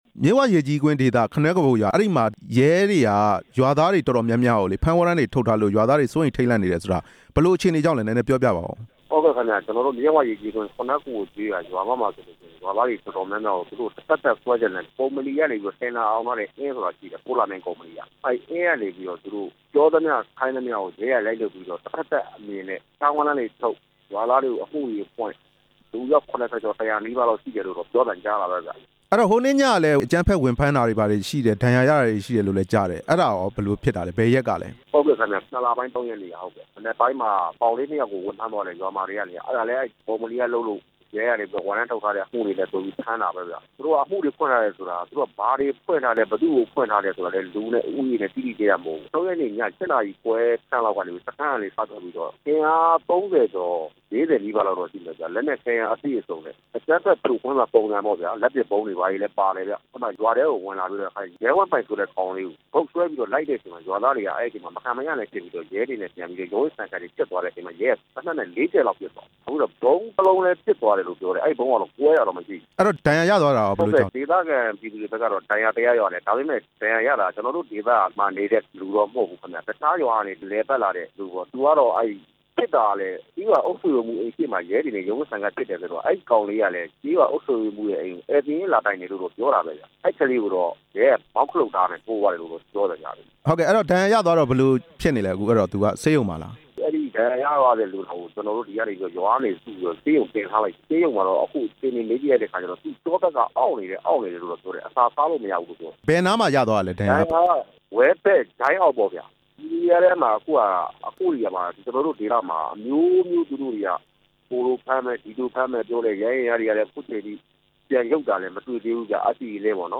ပန်းတနော်မြို့နယ် ခနွဲခဘိုရွာမက အင်းပြဿနာ မေးမြန်းချက်